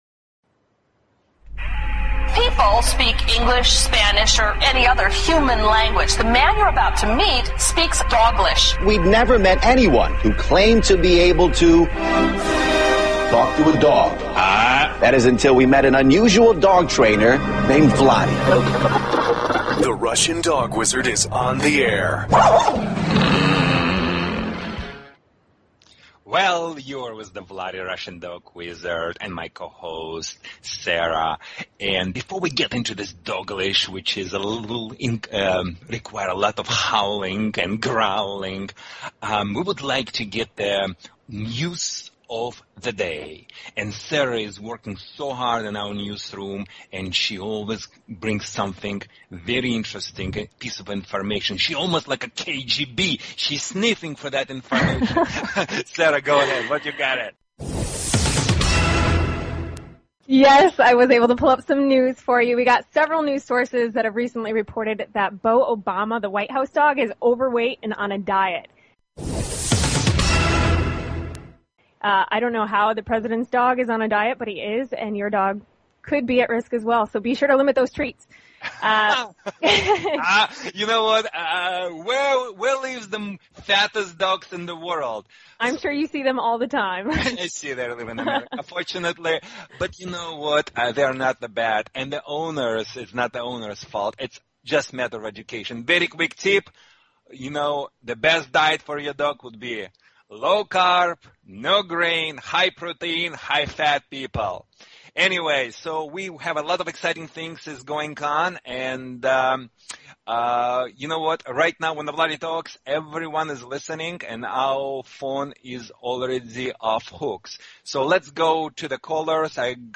Talk Show Episode, Audio Podcast, Doglish_Radio and Courtesy of BBS Radio on , show guests , about , categorized as